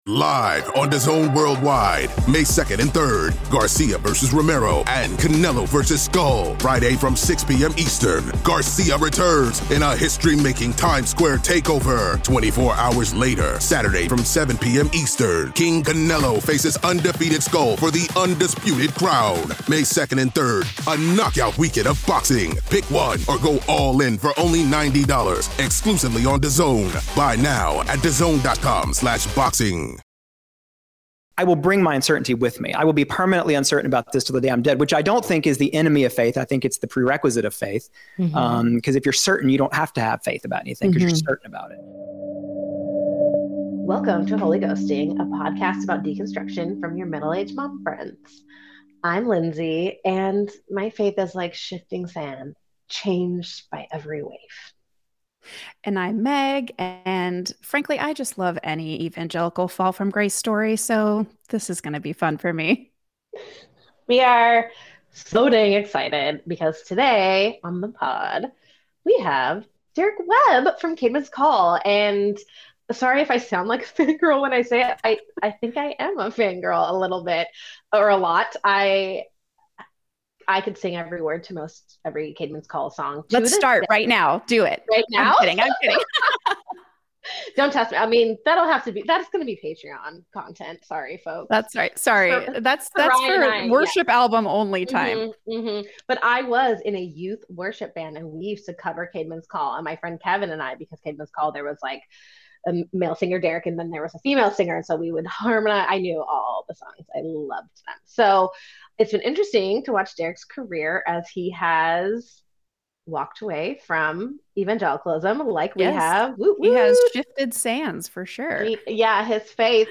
We talked about his CCM days, his “fall from grace”, deconversion, solo career, and all that fun stuff! (We also heard a little from his adorable pup, Etta.) We got into all the nitty gritty of evangelical Christianity and were floored by his kind and graceful way of speaking about the messiness of faith and doubt and everything in between.